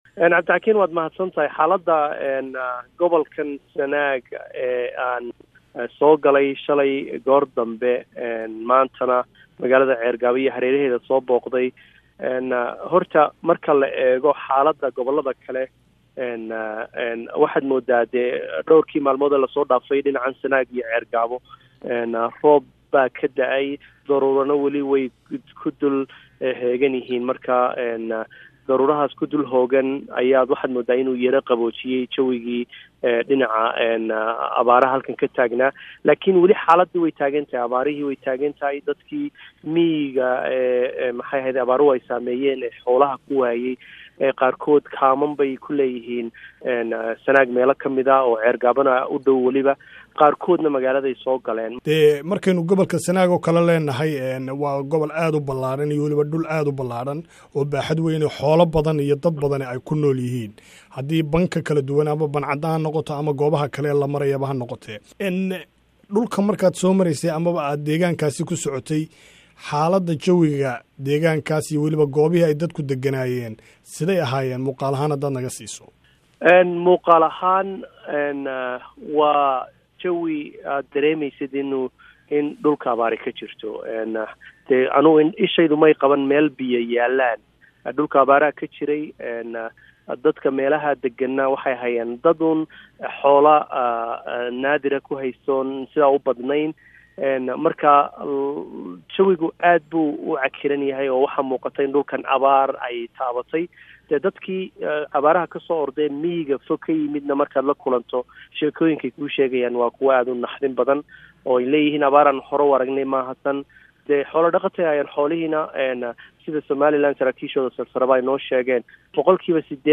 ayaa khadka taleefanka Ceerigaabo kula xiriiray